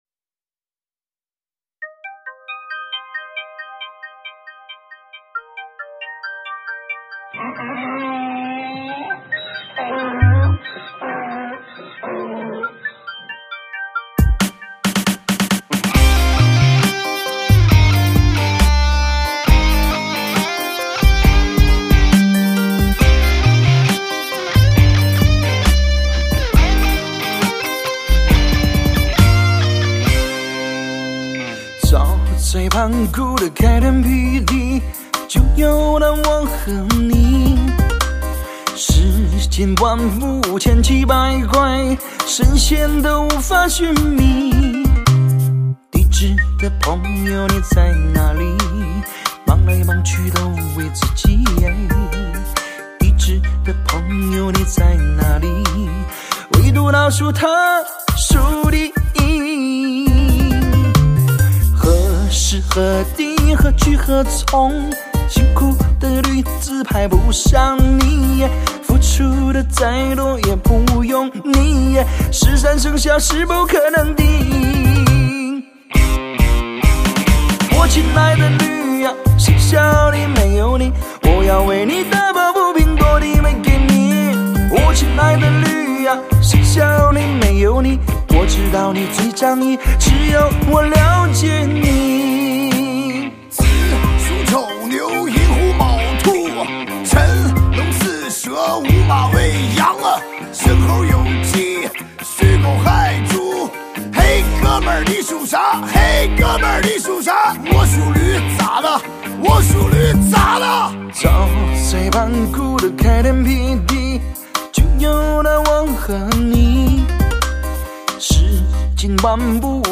其说唱部分“我属驴咋了”经网友口口相传成为继“很黄很暴力”之后，
歌曲将R＆B、摇滚和西域的音乐元素融合在一起，